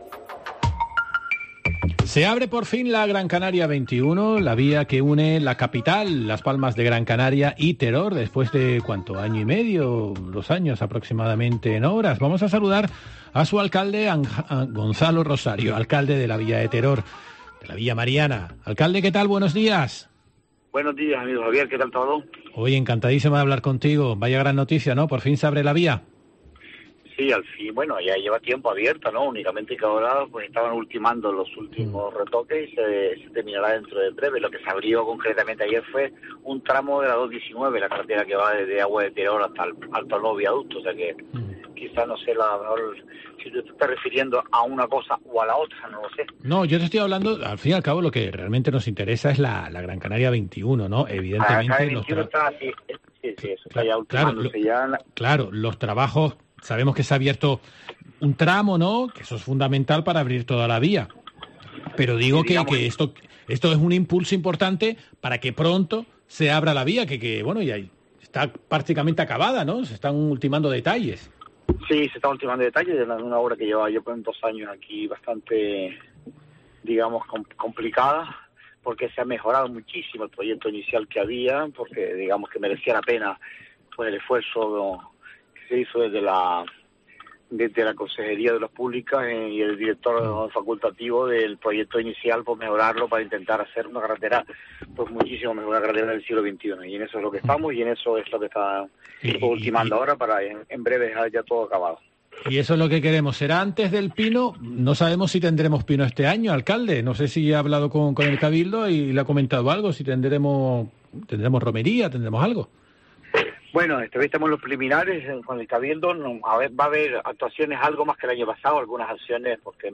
Entrevista con Gonzalo Rosario, alcalde de Teror